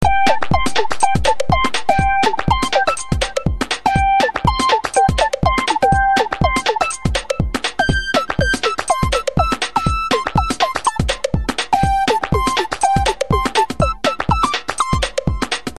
Real Name: misses Strippy Birthday: 10-10-01 Birthplace: Hello Shitty City Favorite Saying: hehehehe theme song strippy_theme.mp3 1.356 kb Misses Strippy is a lovely cuddly strippy cat.